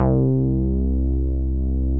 MOOG #2  C3.wav